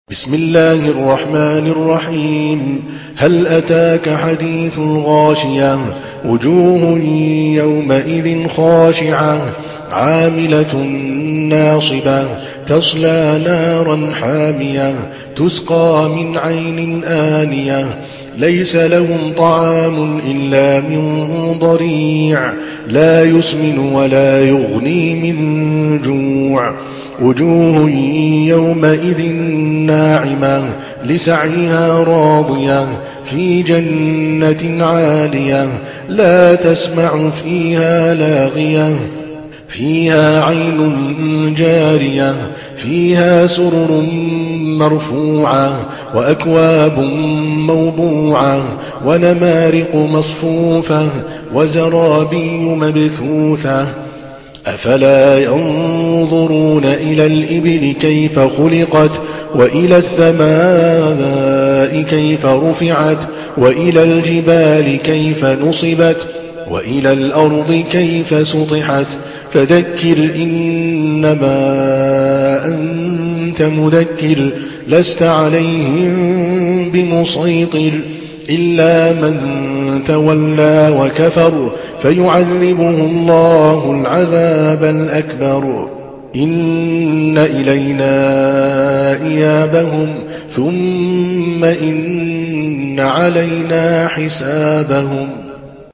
Surah আল-গাশিয়াহ্ MP3 in the Voice of Adel Al Kalbani in Hafs Narration
Surah আল-গাশিয়াহ্ MP3 by Adel Al Kalbani in Hafs An Asim narration.
Murattal Hafs An Asim